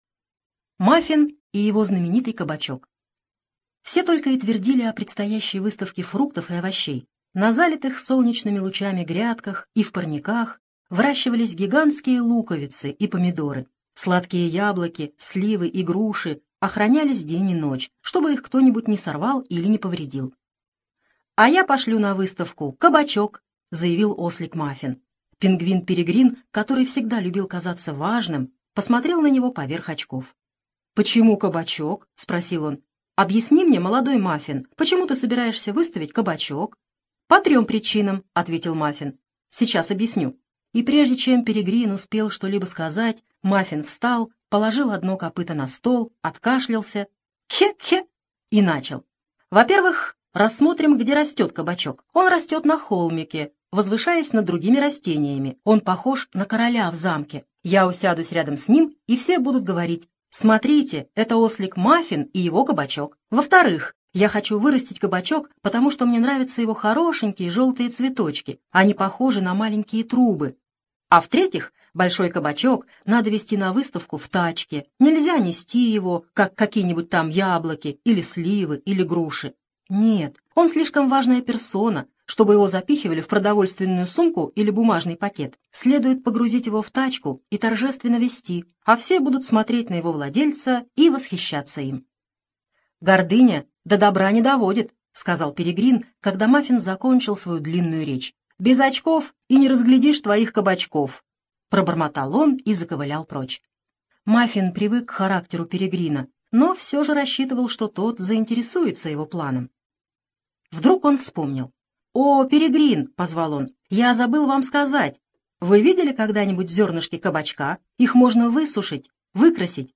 Мафин и его знаменитый кабачок - аудиосказка Хогарт